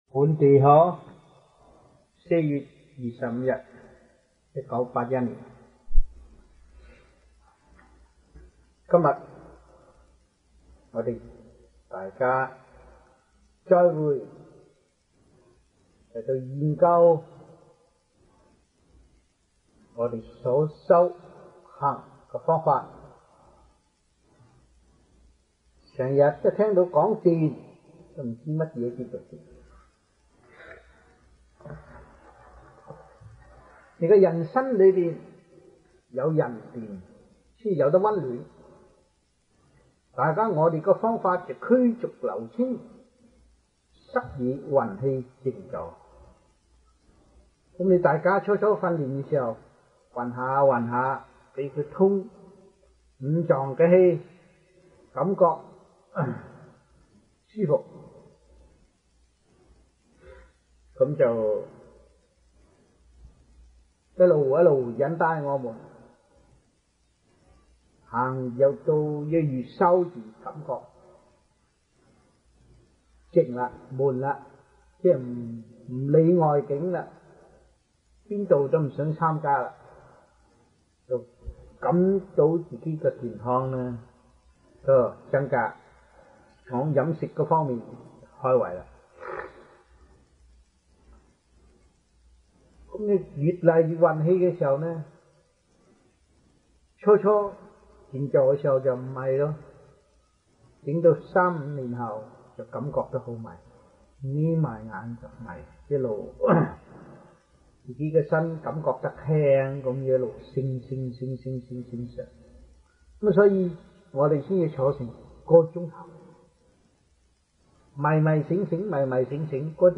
Lectures-Chinese-1981 (中文講座)